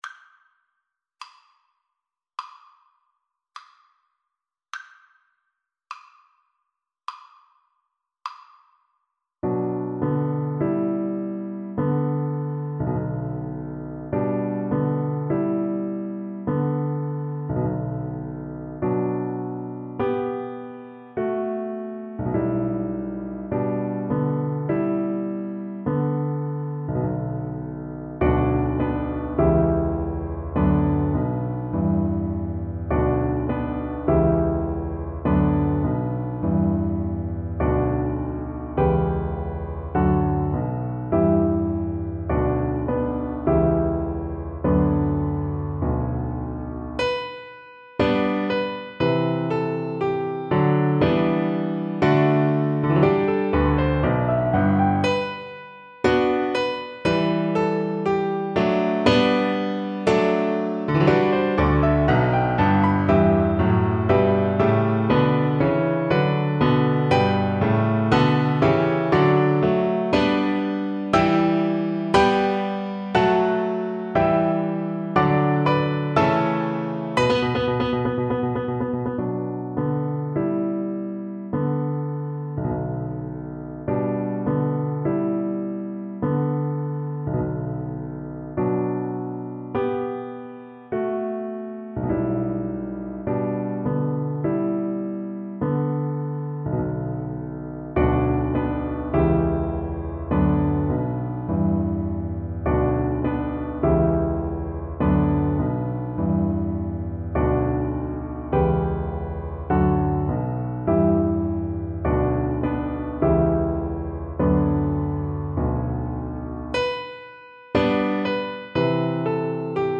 Classical Balakirev, Mily The Song of the Volga Boatmen Violin version
4/4 (View more 4/4 Music)
Andante = 72
B minor (Sounding Pitch) (View more B minor Music for Violin )
Classical (View more Classical Violin Music)
volga_boatmenVLN_kar3.mp3